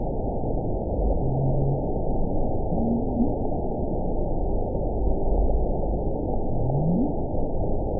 event 919648 date 01/15/24 time 18:30:09 GMT (1 year, 3 months ago) score 6.39 location TSS-AB08 detected by nrw target species NRW annotations +NRW Spectrogram: Frequency (kHz) vs. Time (s) audio not available .wav